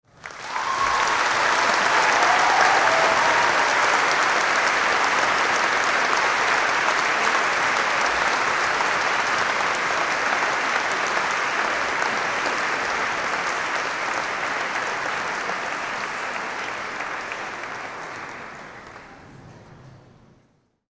Large Audience Applause And Cheering Sound Effect
Large-audience-applause-and-cheering-sound-effect.mp3